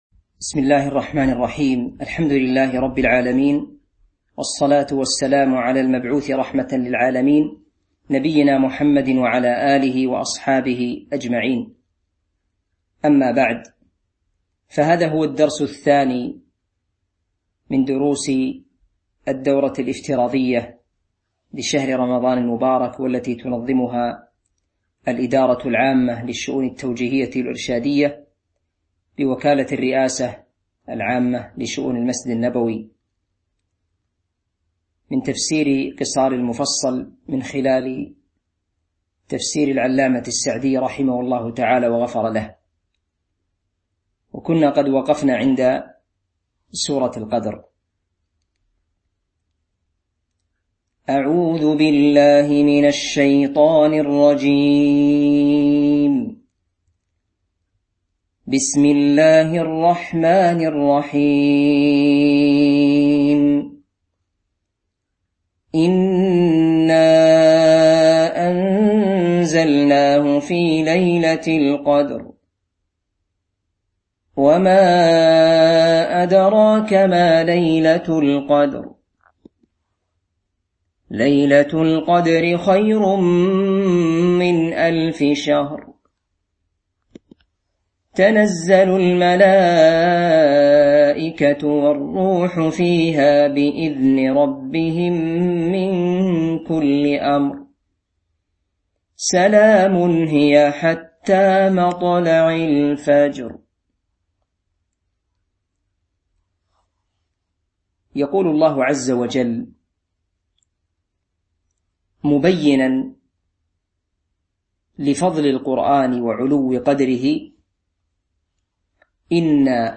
تاريخ النشر ١٠ رمضان ١٤٤٢ هـ المكان: المسجد النبوي الشيخ